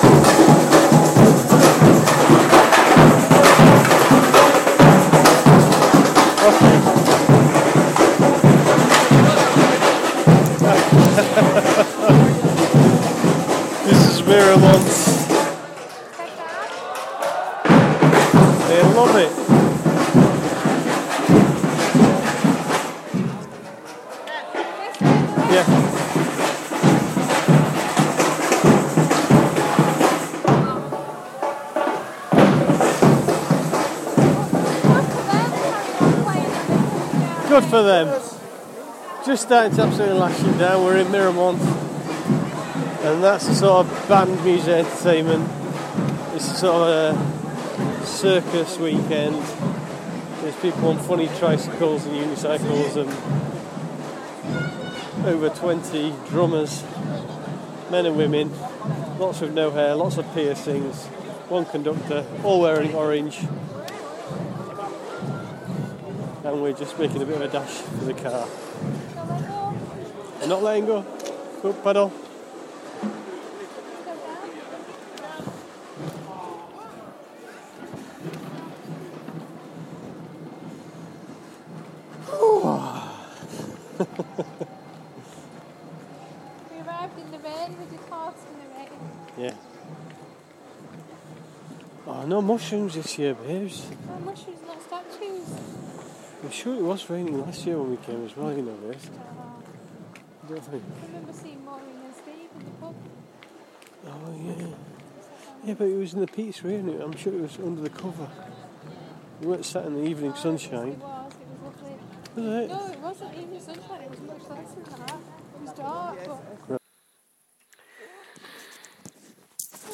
Drums at Miramont circus art weekend